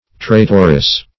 Traitoress \Trai"tor*ess\, n.